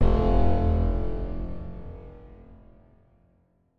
Звуки game over